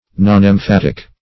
Search Result for " nonemphatic" : The Collaborative International Dictionary of English v.0.48: Nonemphatic \Non`em*phat"ic\, Nonemphatical \Non`em*phat"ic*al\, a. Having no emphasis; unemphatic.